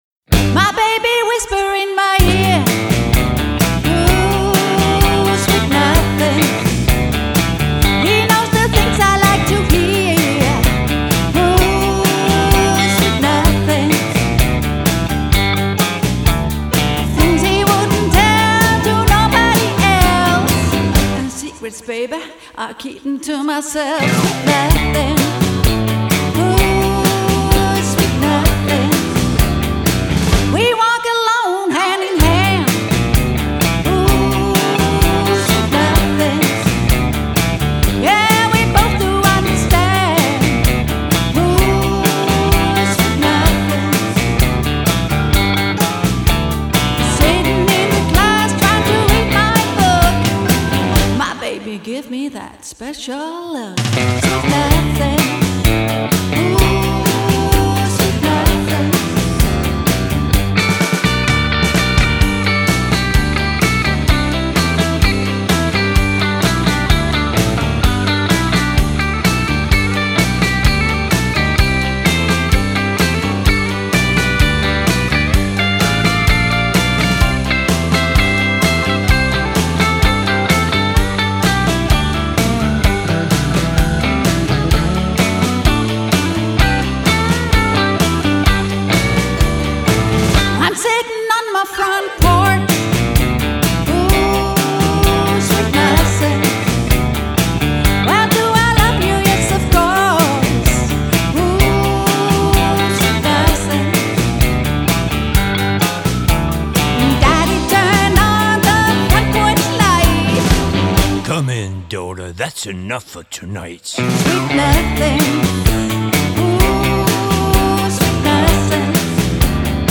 guitar og vokal
keyboards og vokal
• Coverband